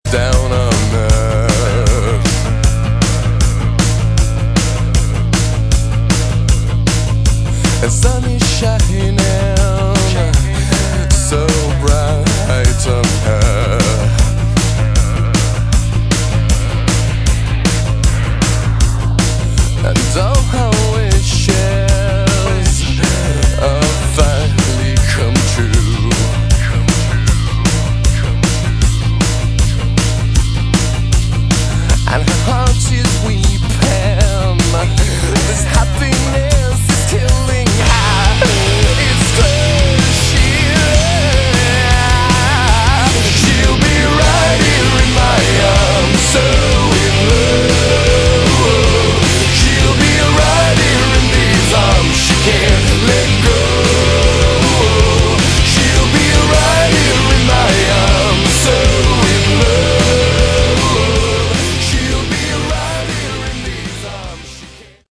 Category: Rock